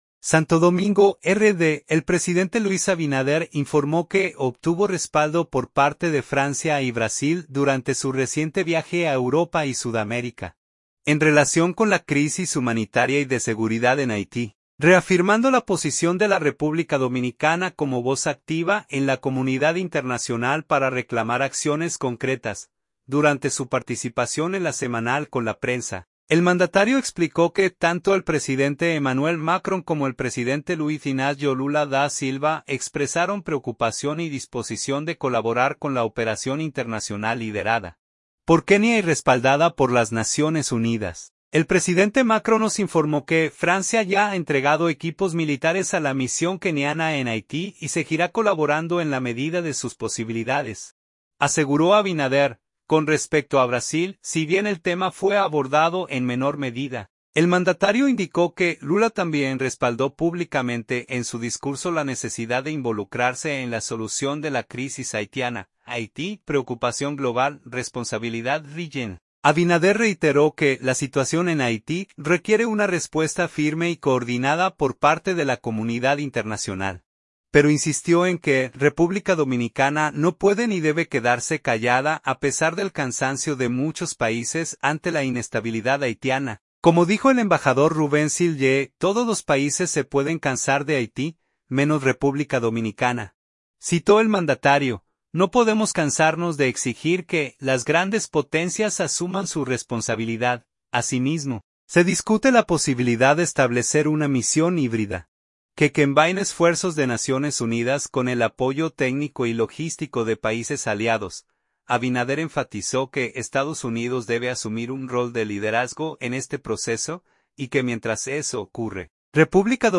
Durante su participación en la “Semanal con la Prensa”, el mandatario explicó que tanto el presidente Emmanuel Macron como el presidente Luiz Inácio Lula da Silva expresaron preocupación y disposición de colaborar con la operación internacional liderada por Kenia y respaldada por las Naciones Unidas.